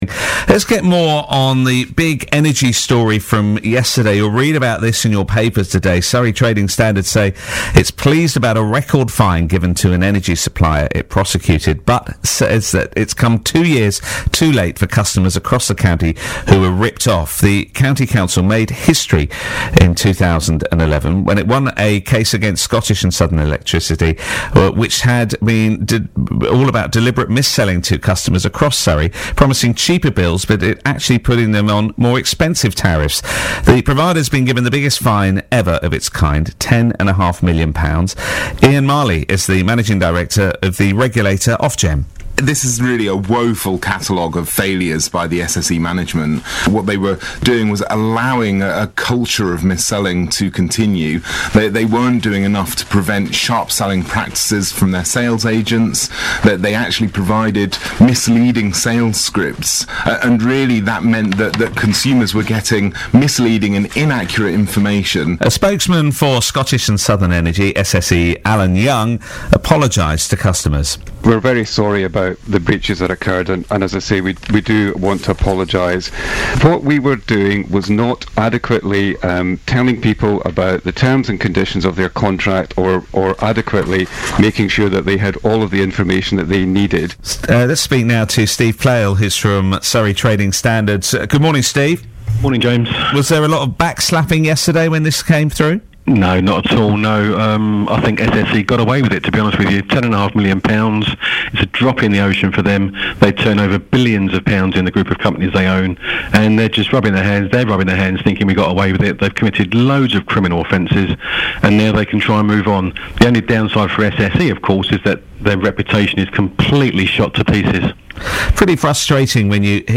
BBC radio interview: SSE fined £10.5 million over sales tricks